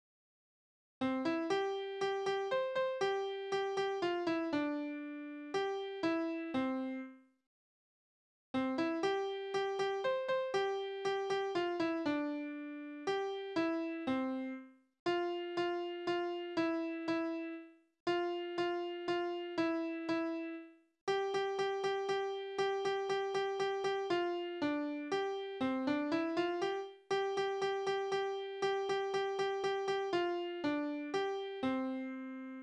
Beruhigungsverse: Jungfer Lieschen
Tonart: C-Dur
Taktart: 6/8
Tonumfang: Oktave
Besetzung: vokal